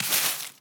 sweeping_broom_leaves_03.wav